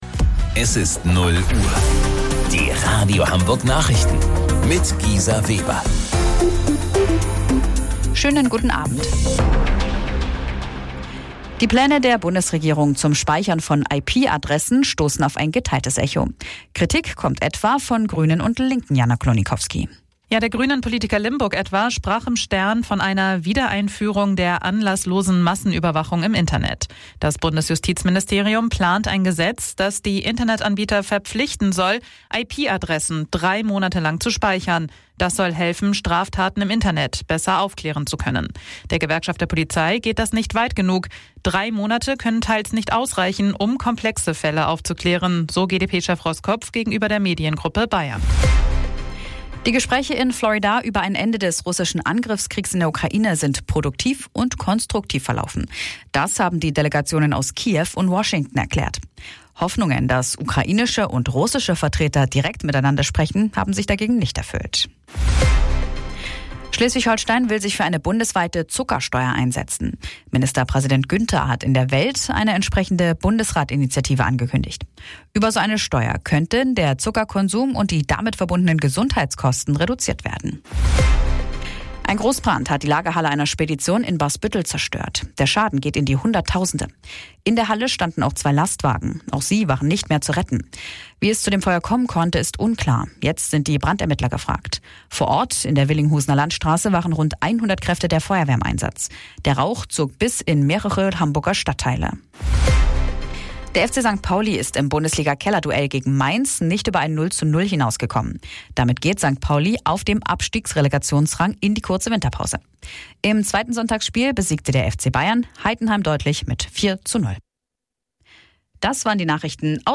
Radio Hamburg Nachrichten vom 22.12.2025 um 00 Uhr